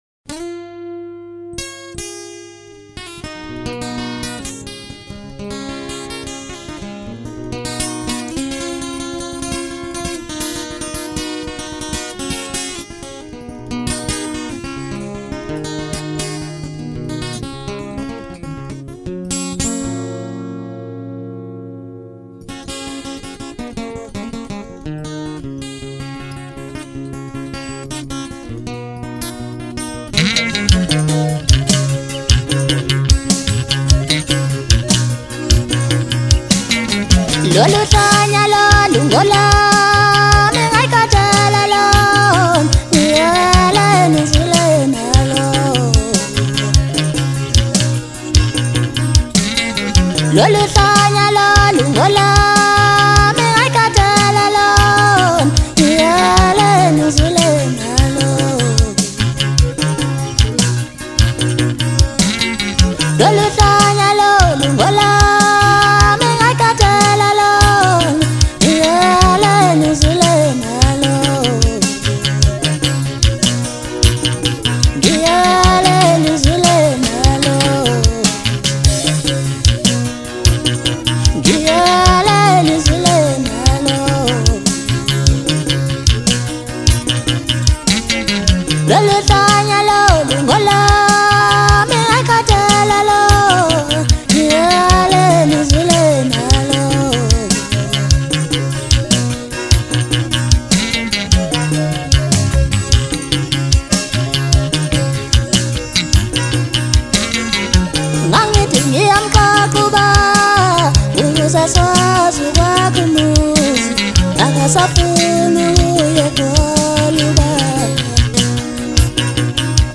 Maskandi singer
Genre : Maskandi